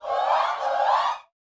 SHOUTS21.wav